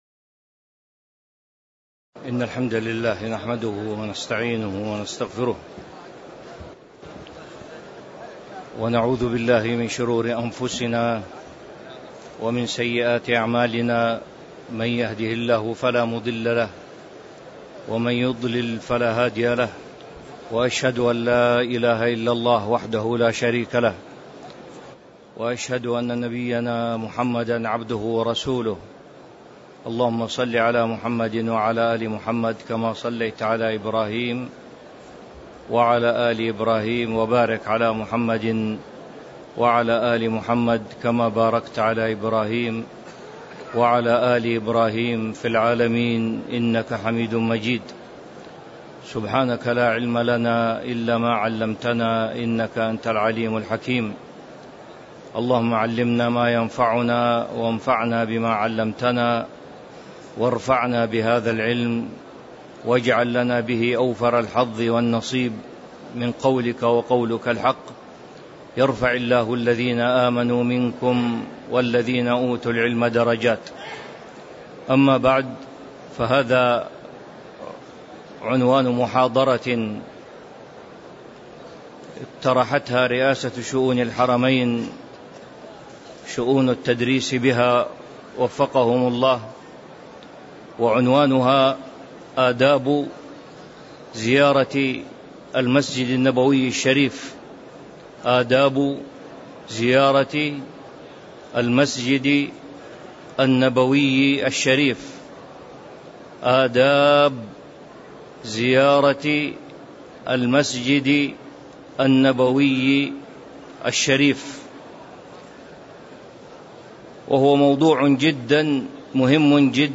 تاريخ النشر ٢٠ رمضان ١٤٤٤ هـ المكان: المسجد النبوي الشيخ